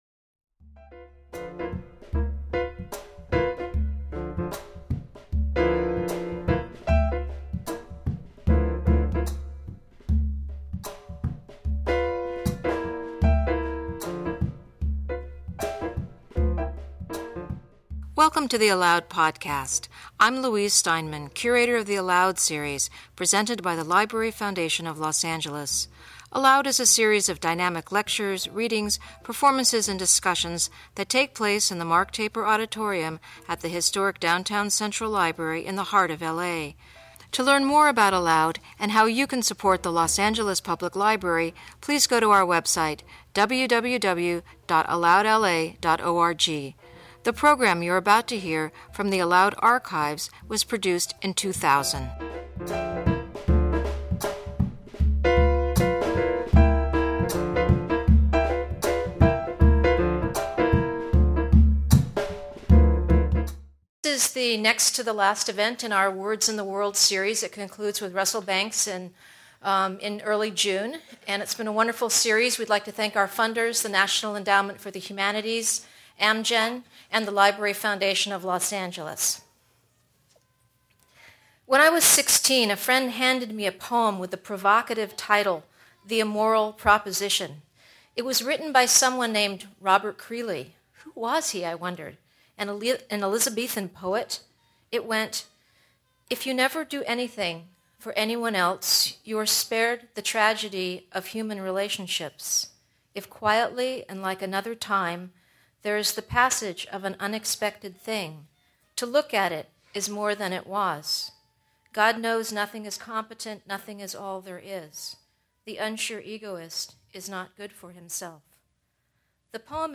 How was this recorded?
ALOUD at Central Library